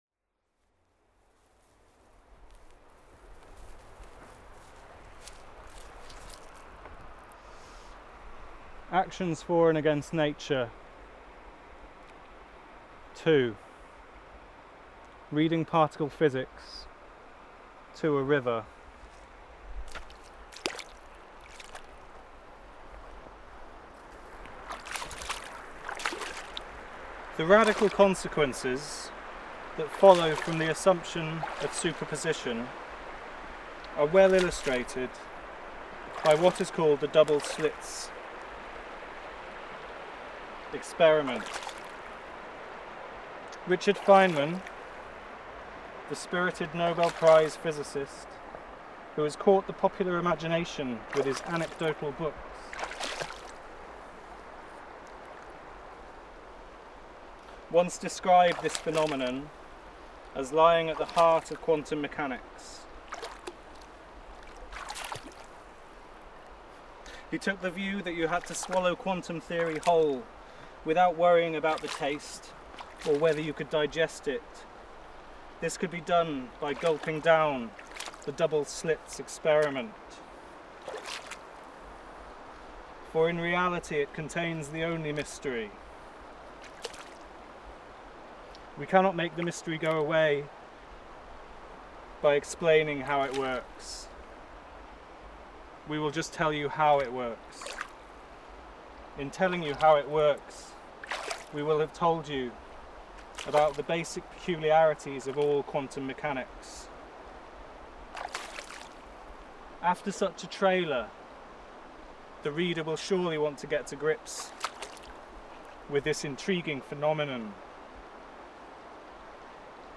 Reading-Particle-Physics-to-A-River-Final-Edit_mixdown_01.mp3